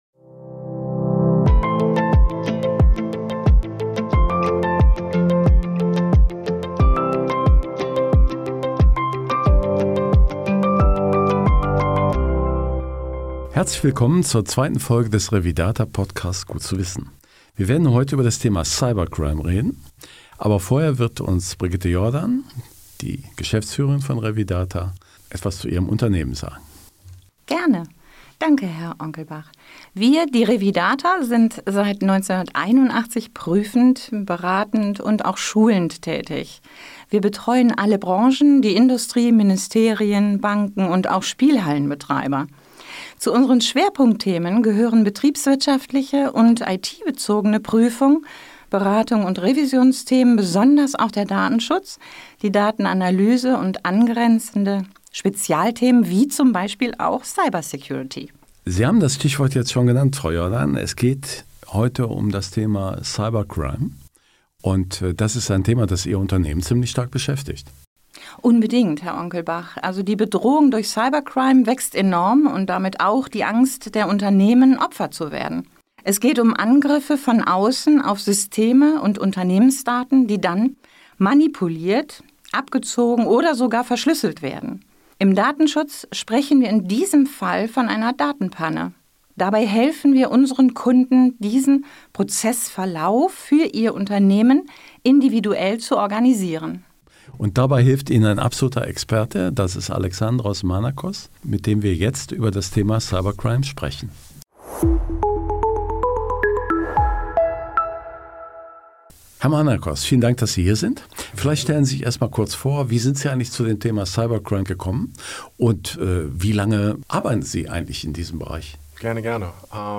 Daher ist er auch der perfekte Gesprächspartner in diesem Podcast.